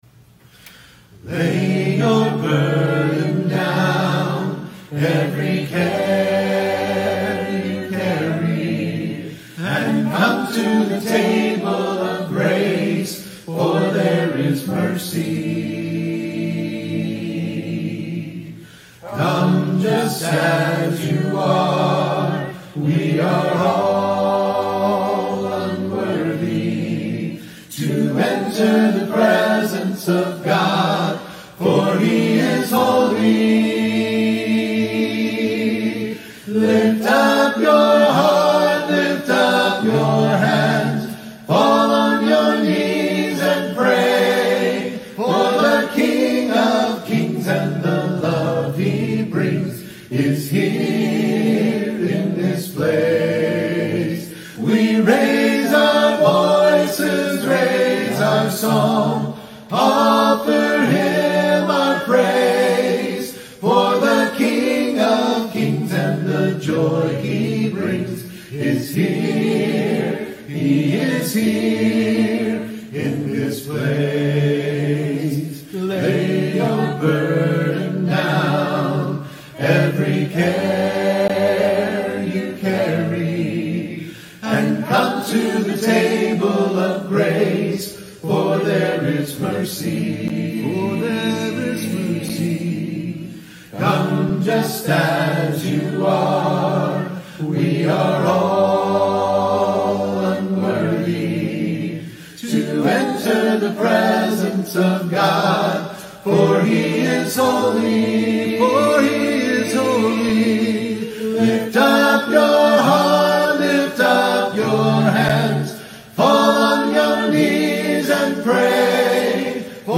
Prayer Service